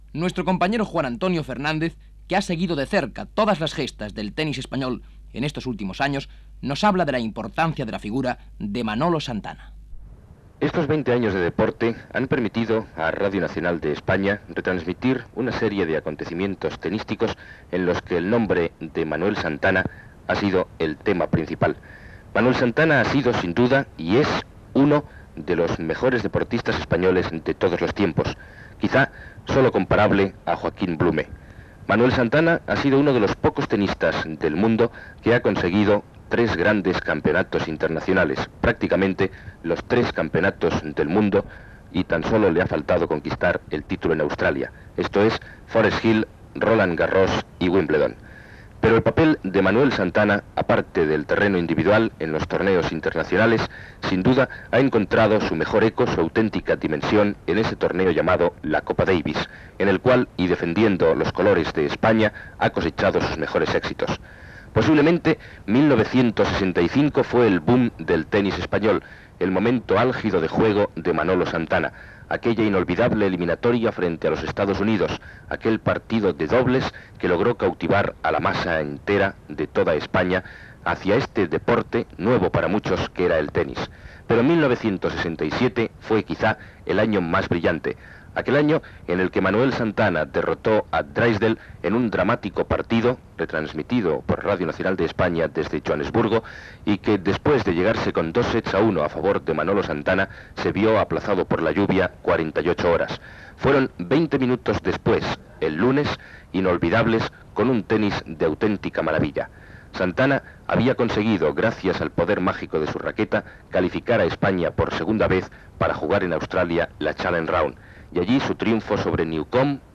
Esportiu